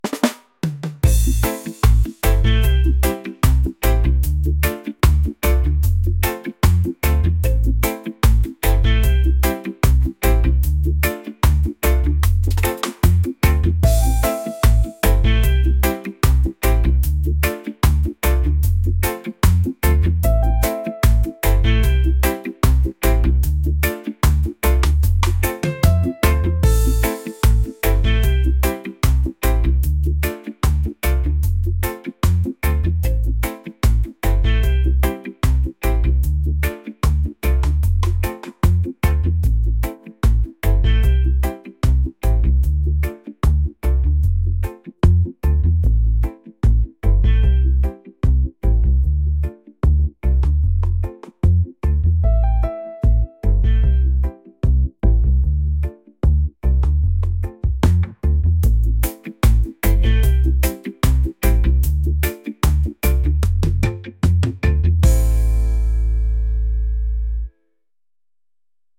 reggae | romantic | laid-back